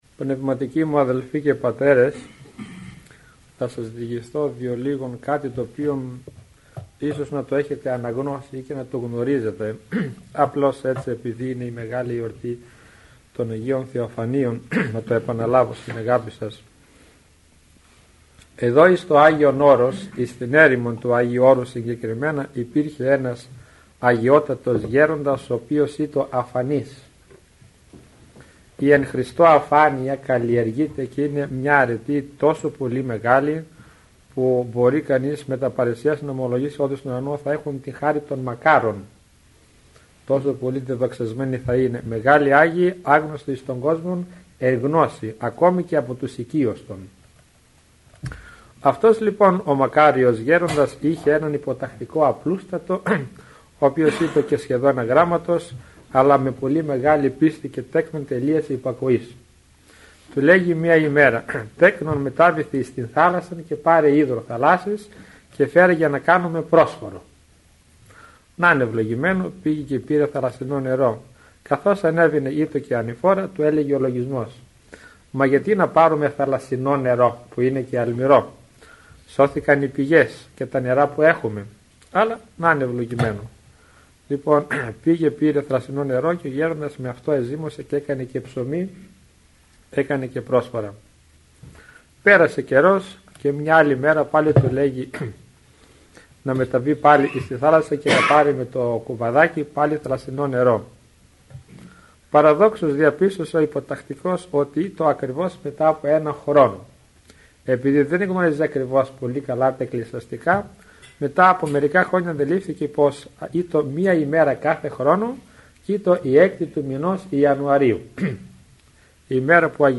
Τα Άγια Θεοφάνεια και ο τίμιος Πρόδρομος – ηχογραφημένη ομιλία